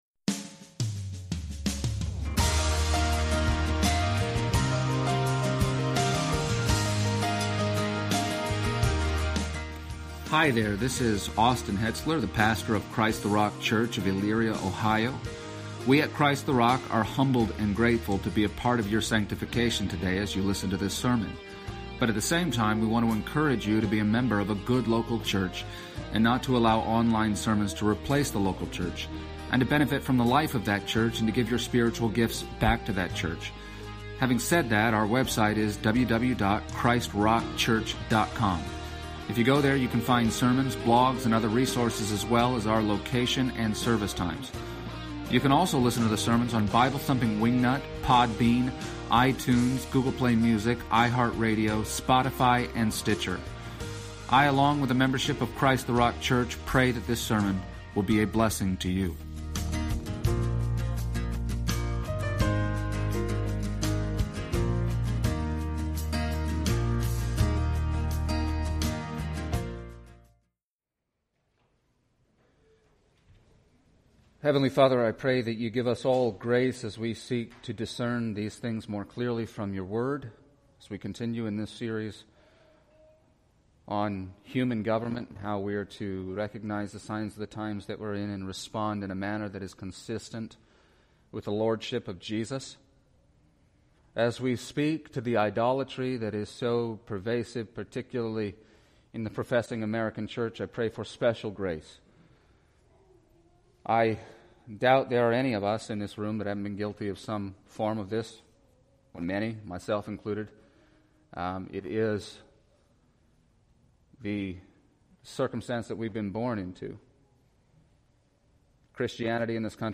and the Christian Service Type: Sunday Morning %todo_render% « American Idolatry